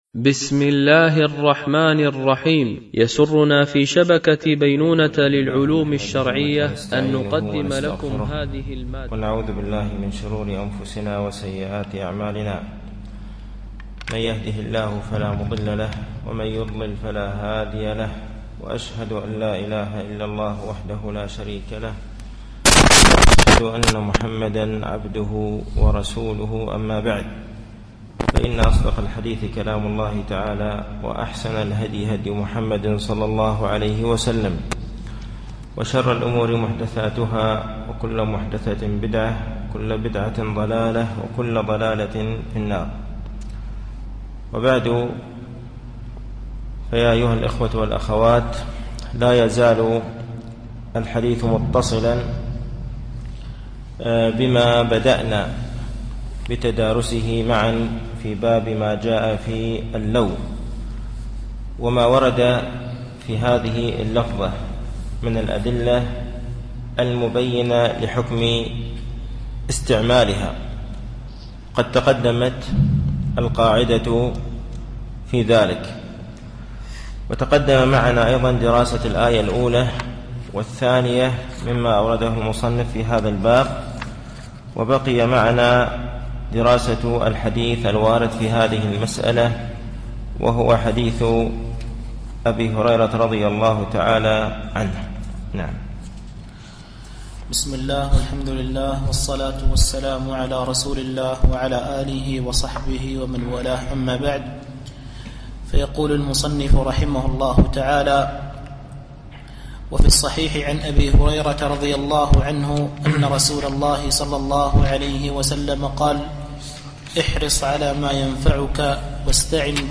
التعليق على القول المفيد على كتاب التوحيد ـ الدرس التاسع و الأربعون بعد المئة